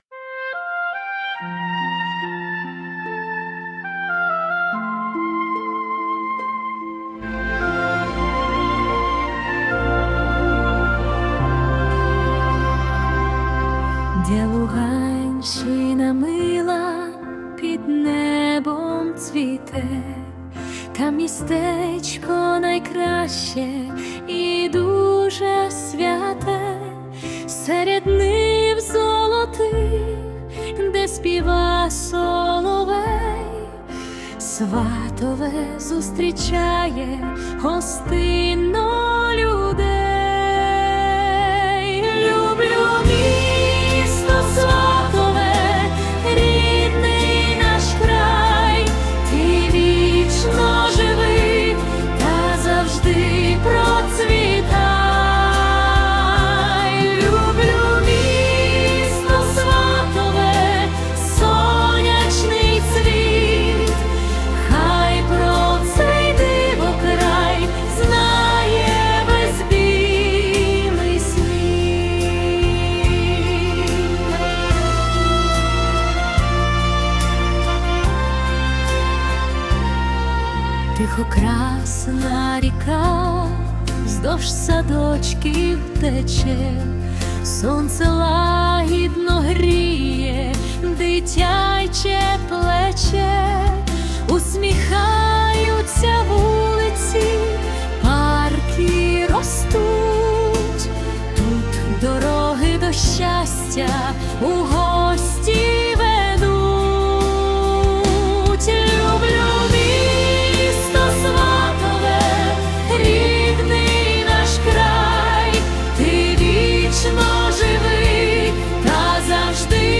Оркестрова поп-музика / Дитячий хор
Дитячі голоси Луганщини